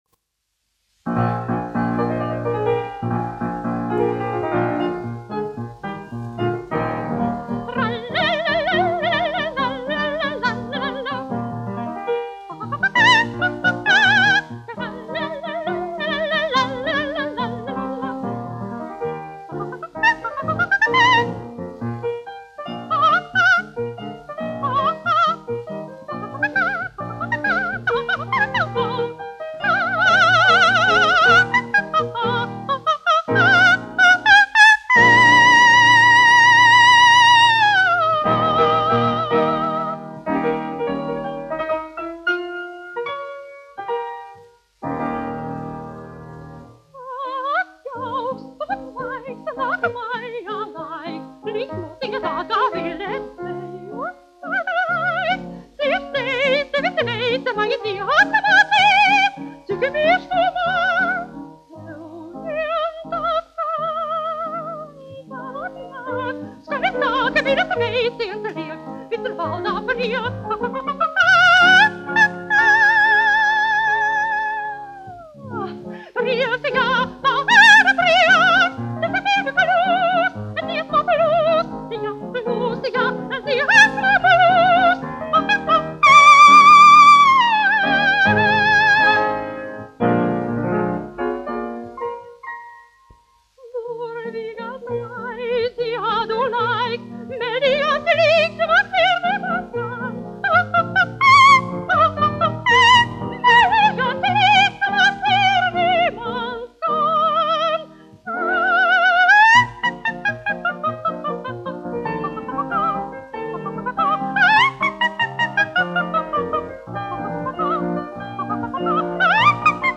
1 skpl. : analogs, 78 apgr/min, mono ; 25 cm
Valši
Latvijas vēsturiskie šellaka skaņuplašu ieraksti (Kolekcija)